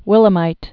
(wĭlə-mīt)